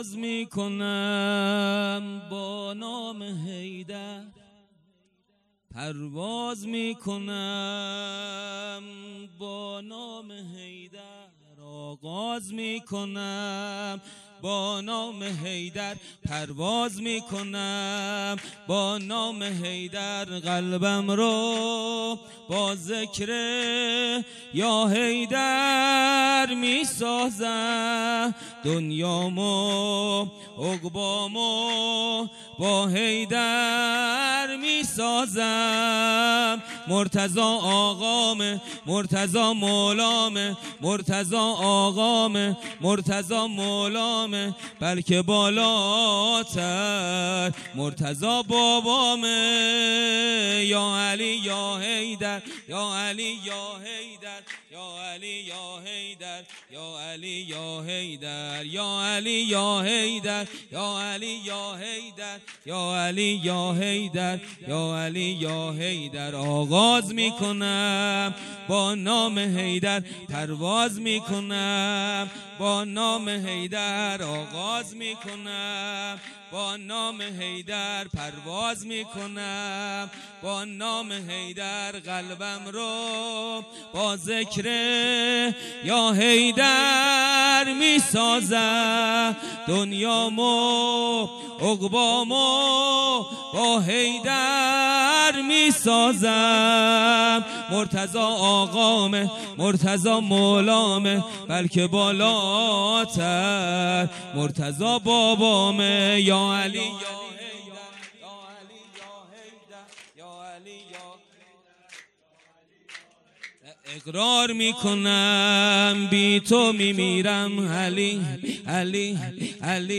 خیمه گاه - هیئت ذبیح العطشان کرمانشاه - ولادت امام حسین(ع)-سرود امام علی(ع)
هیئت ذبیح العطشان کرمانشاه